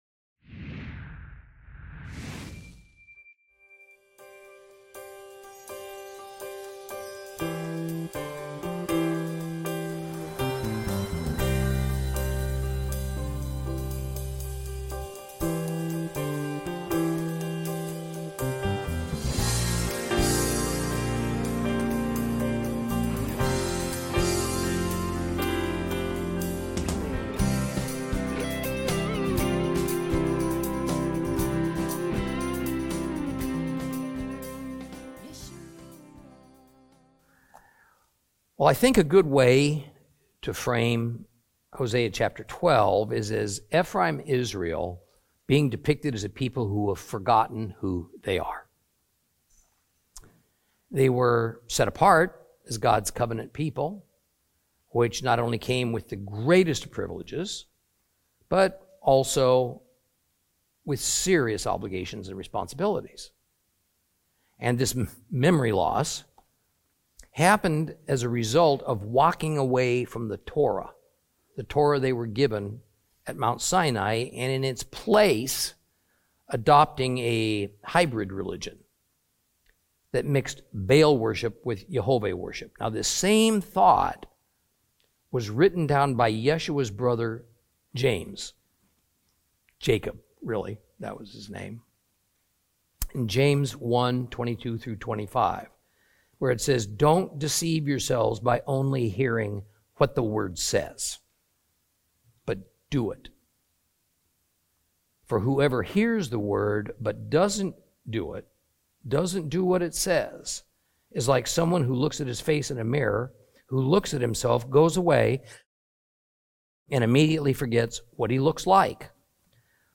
Teaching from the book of Hosea, Lesson 21 Chapter 12.